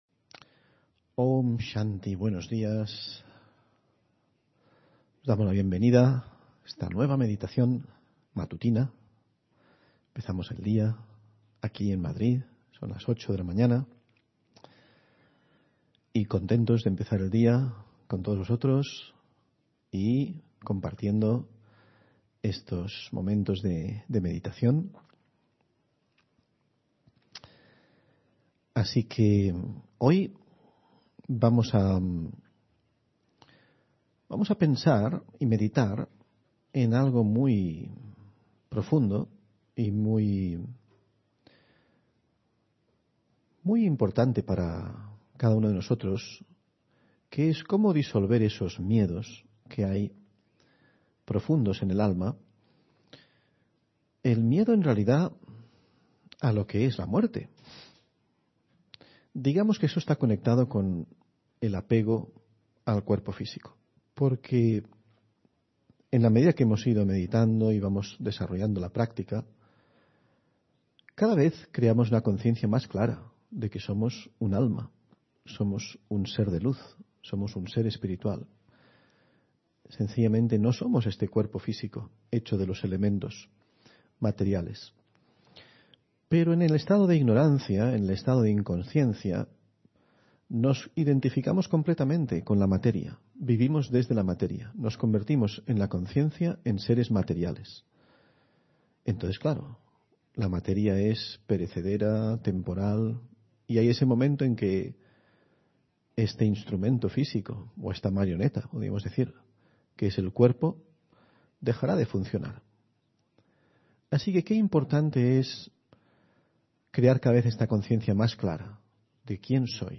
Meditación de la mañana: Desapego del cuerpo